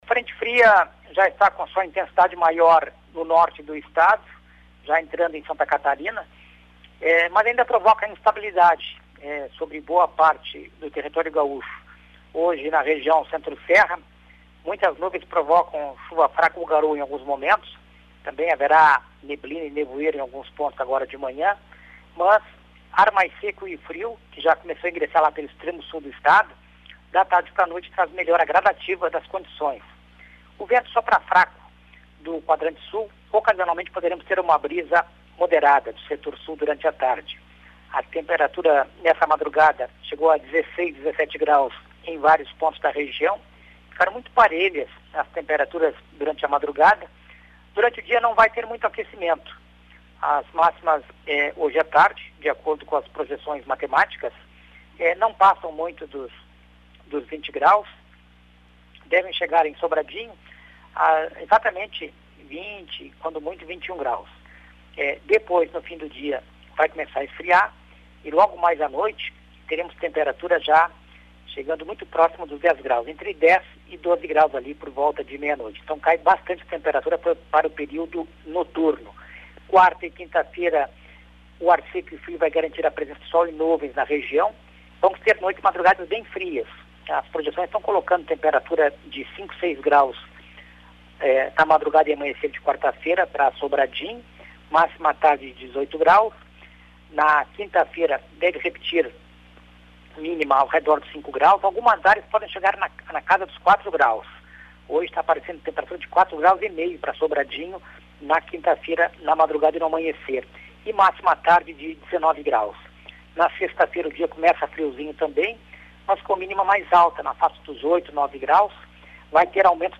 previsão do tempo para o Centro Serra